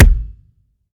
Hitsound
normal-hitnormal.mp3